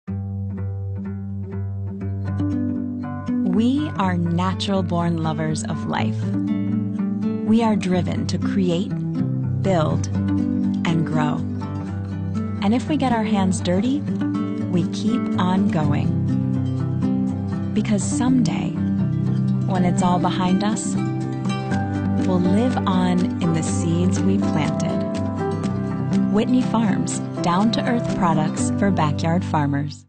Female
Adult (30-50)
Warm, conversational, girl next door, bright, natural, inviting, reassuring, clear, comforting, hip, urban, best friend. Sultry, sassy, and characters.
Television Spots
All our voice actors have professional broadcast quality recording studios.